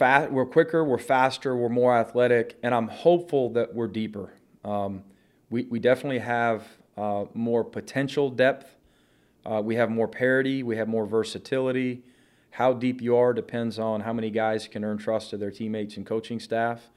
Florida basketball head coach Mike White met with media today. He discussed the impact of the coronavirus had on summer workouts, as well as changes he aims to make on the court.